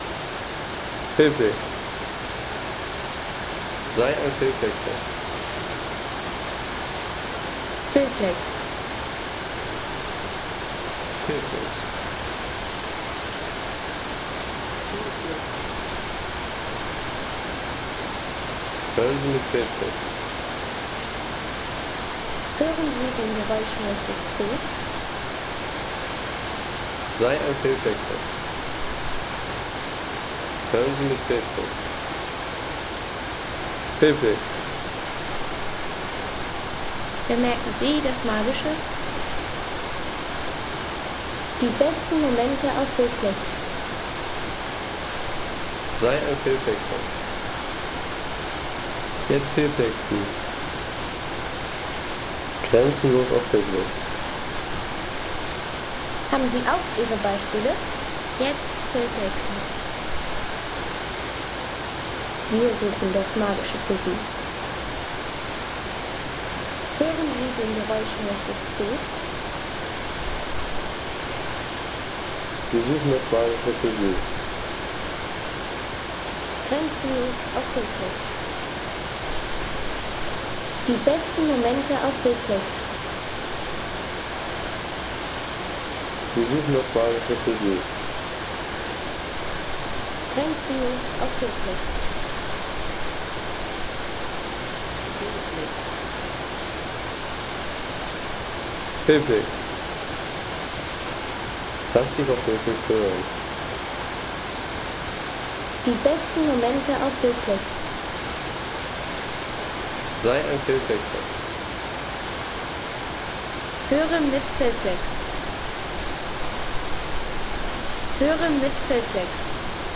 Cascata del Toce Wasserfall
Erfrischung am Wasserfall: Naturgenuss an heißen Sommertagen.